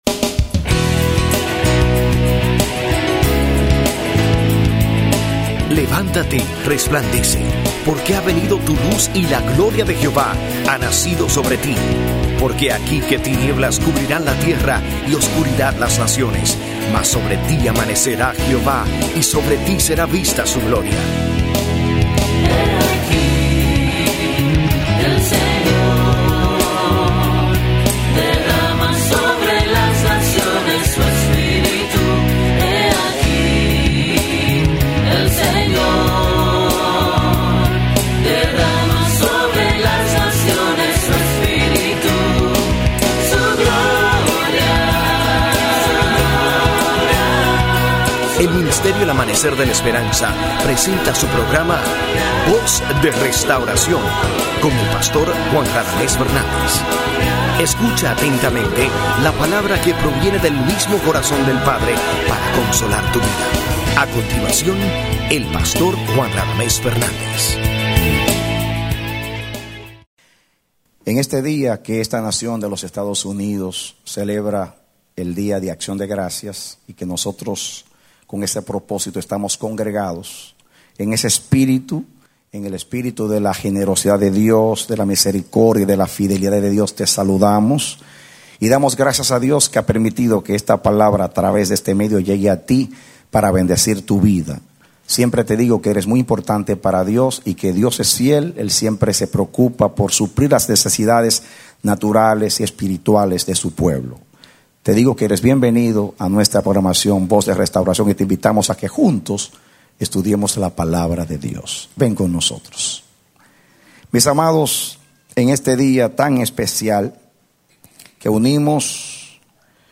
Predicado Noviembre 27, 2008